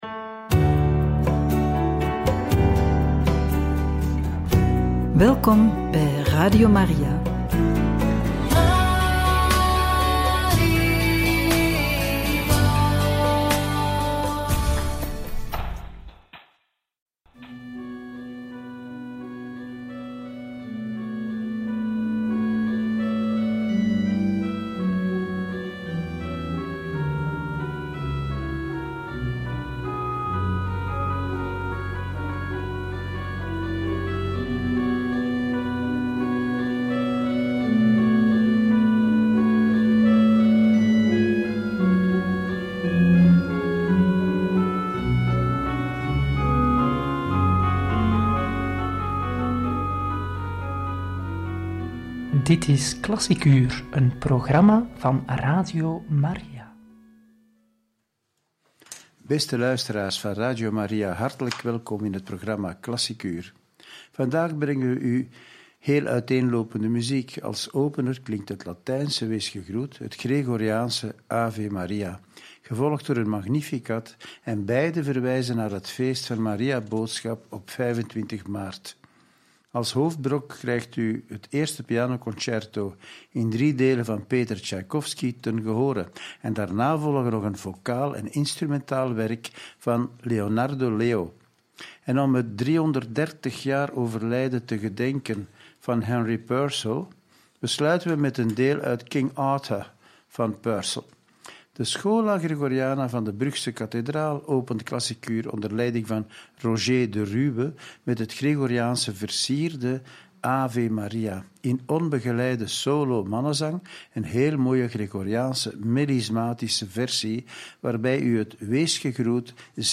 Liederen tot Onze-Lieve-Vrouw, een pianoconcerto en ‘King Arthur’ van Purcell – Radio Maria